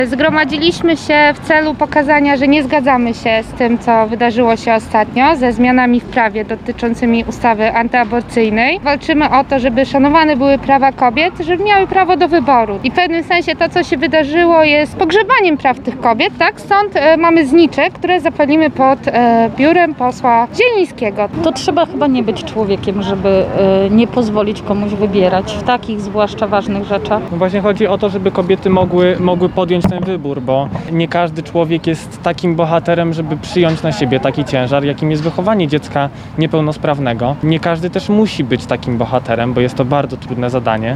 protest1.mp3